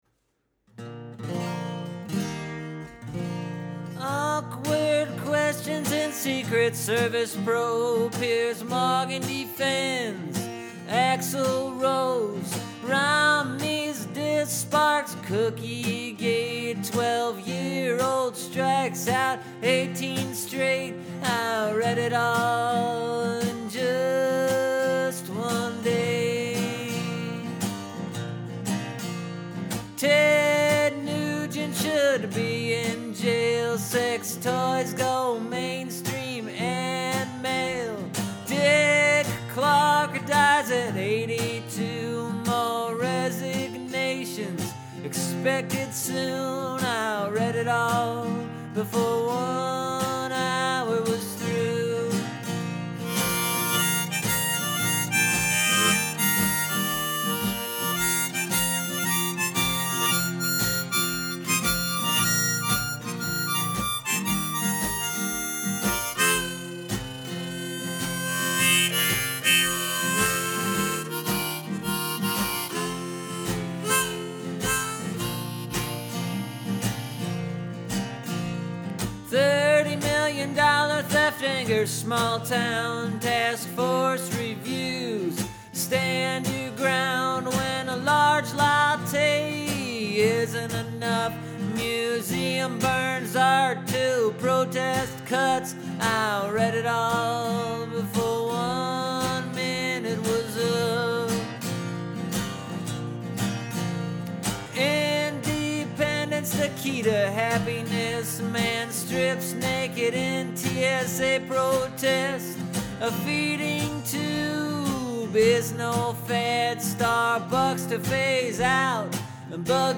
Here’s the version of Talkin’ Headline that I performed live on WBEZ’s Eight Forty-Eight on Friday, April 20.
Yeah, I did holster up the wrong mouth harp for the video.